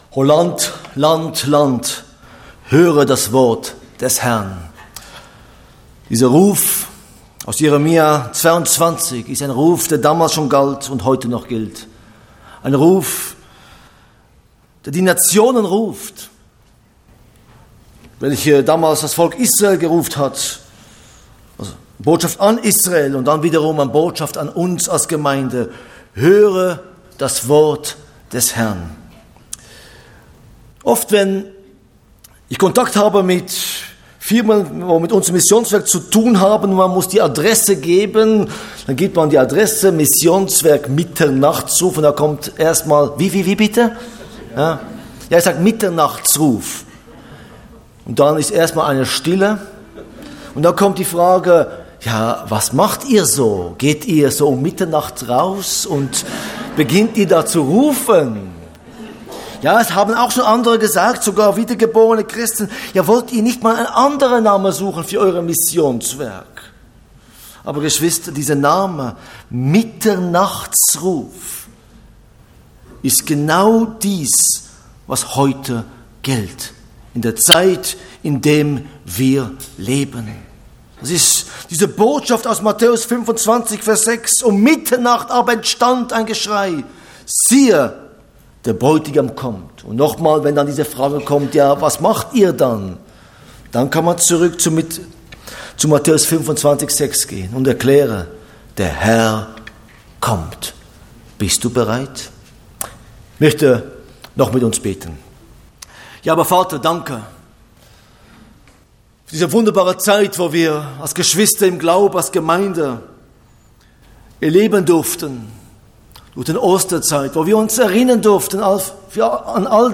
Botschaft